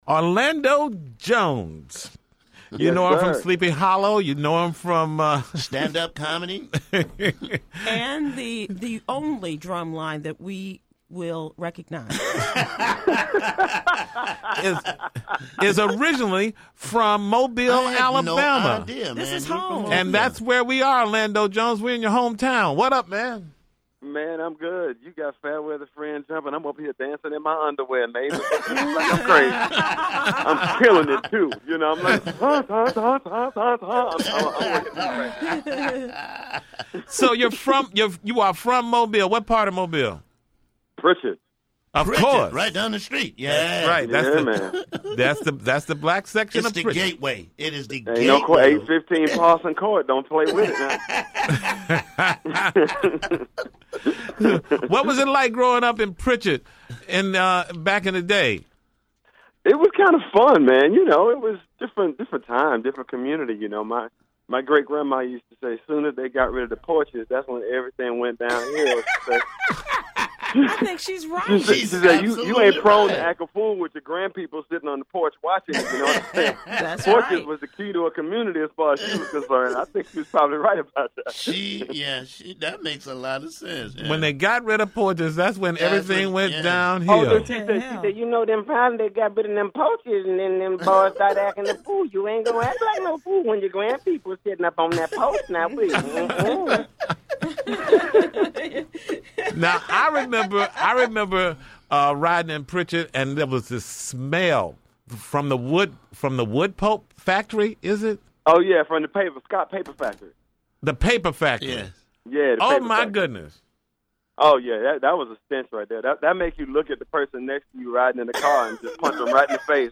That’s where the Tom Joyner Morning Show is today. Jones is a native of Pritchard and says he had fun growing up there.